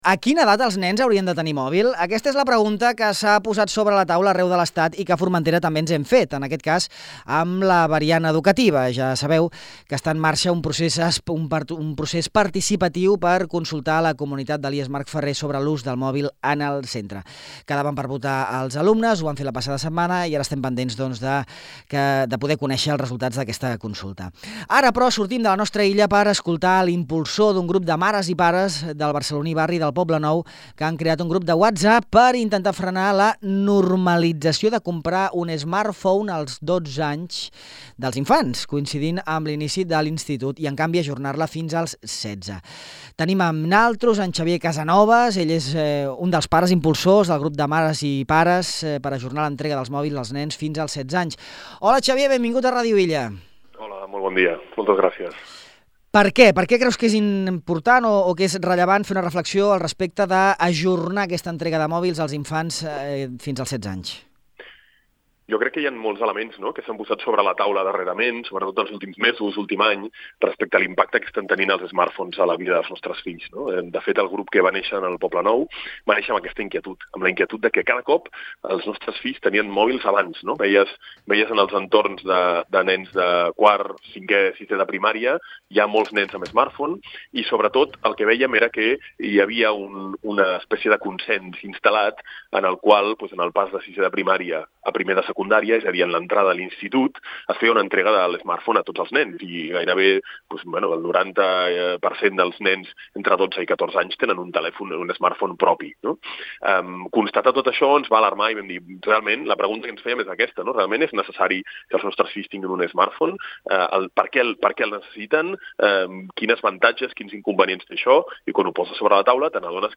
En aquesta entrevista, explica a Ràdio Illa els motius que han portat a un col·lectiu de pares del barceloní barri del Poblenou a emprendre una iniciativa que s’ha estès arreu de l’Estat i que en l’àmbit educatiu, també ha arribat a Formentera.